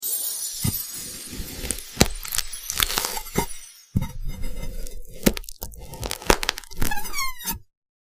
🔊You're slicing the glitter Google jelly cube.